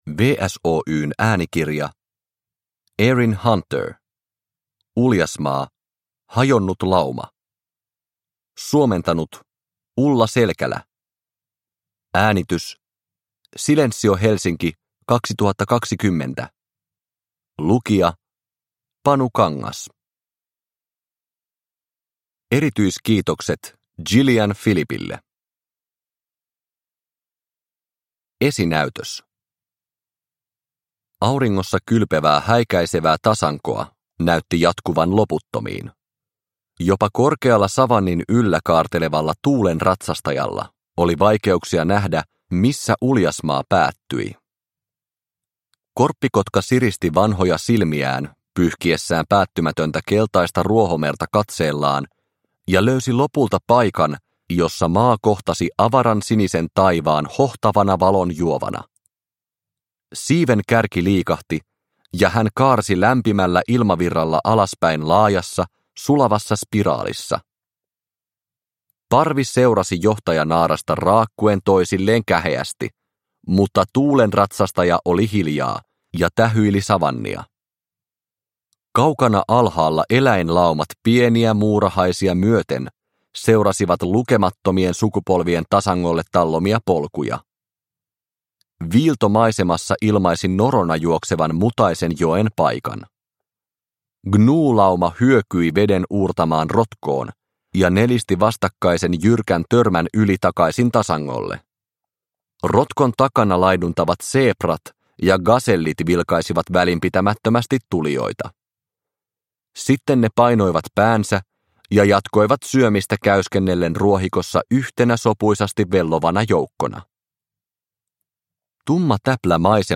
Uljasmaa: Hajonnut lauma – Ljudbok – Laddas ner